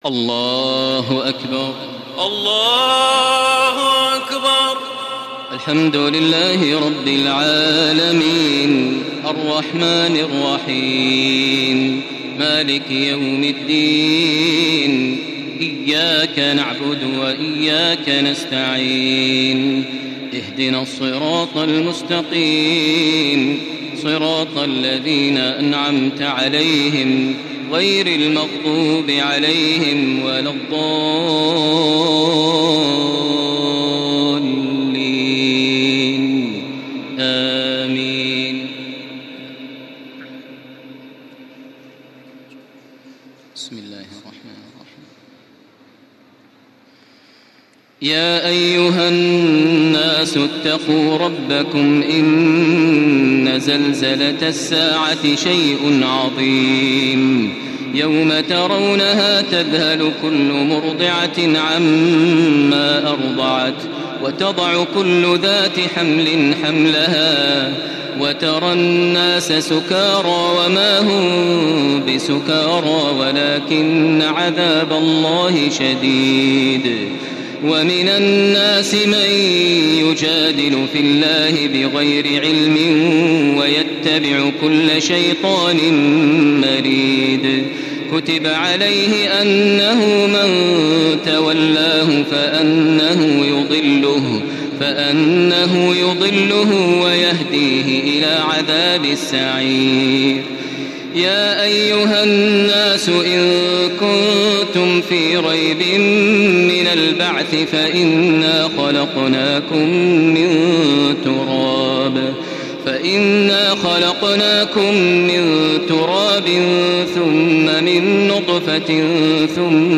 تراويح الليلة السابعة عشر رمضان 1435هـ سورة الحج كاملة Taraweeh 17 st night Ramadan 1435H from Surah Al-Hajj > تراويح الحرم المكي عام 1435 🕋 > التراويح - تلاوات الحرمين